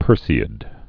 (pûrsē-ĭd)